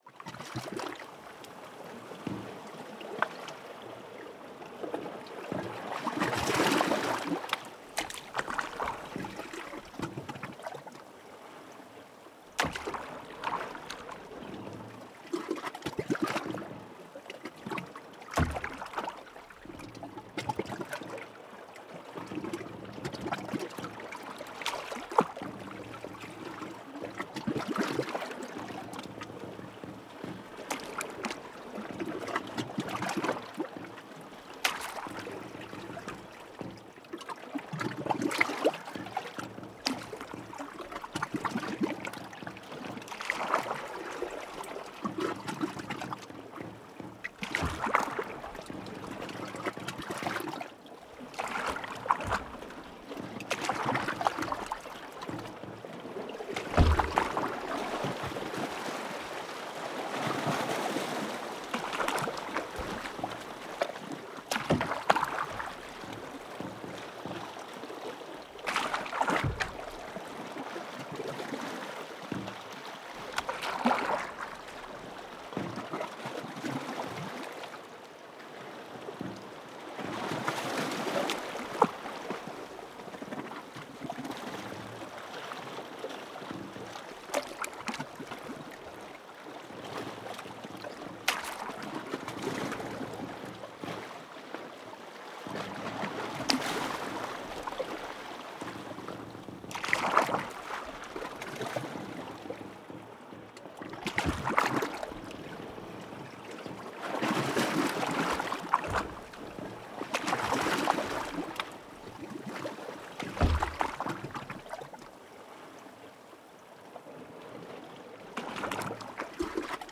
SFX_Scene02_Wood_Boat.mp3